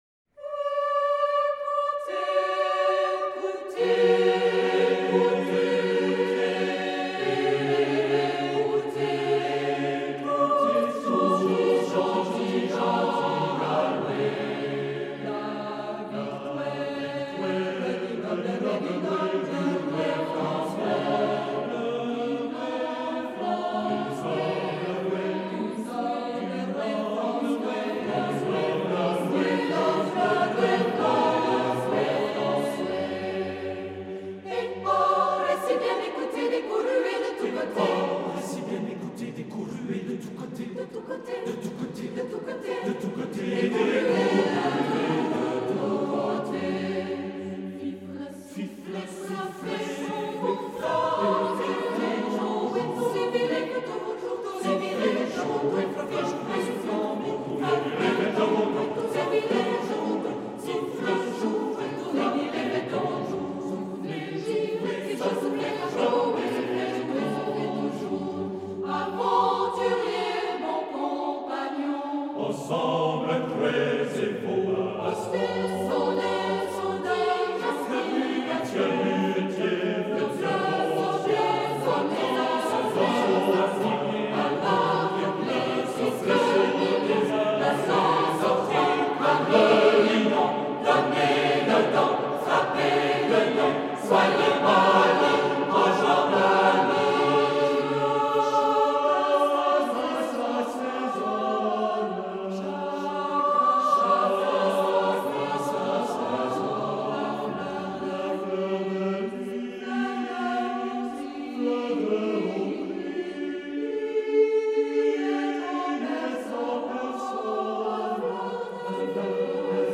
Oeuvre pour choeur SATB a cappella
Cette édition est fidèle à source, dans le ton de sol.